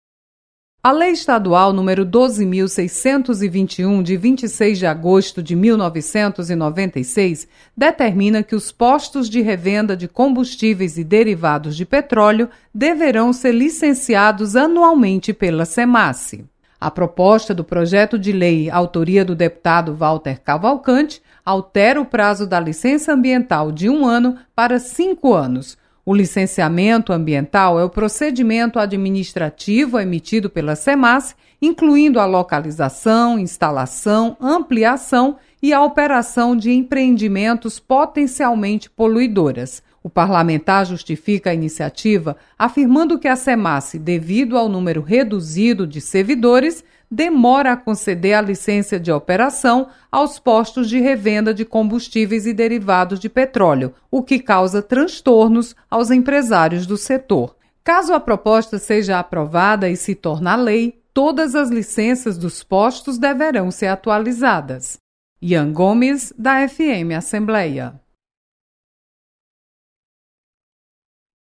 Postos de combustíveis poderão ter novo prazo para licenciamento ambiental. Repórter